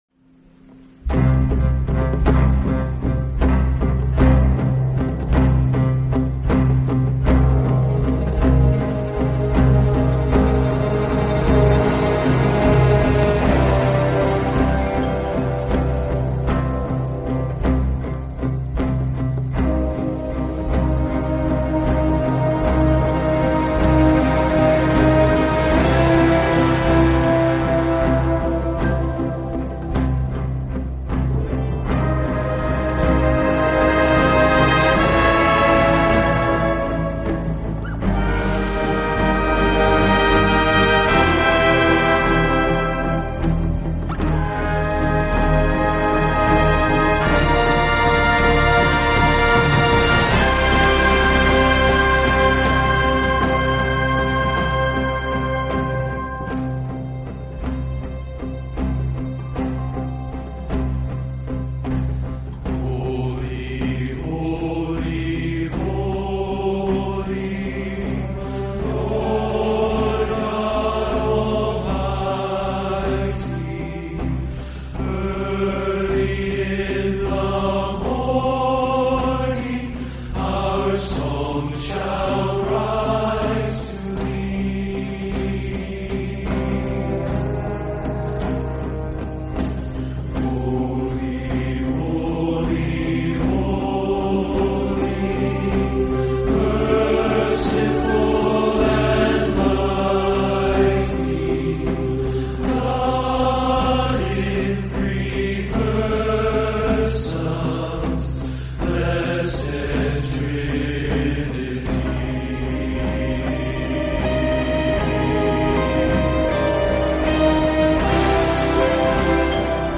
“Holy, Holy, Holy, Lord God Almighty” performed by First Baptist Dallas Church and Orchestra, composed by Reginald Heber.
“Mary Did You Know?” from the album The King’s Men Christmas. Performed by The King’s Men, composed by Mark Alan Lowry.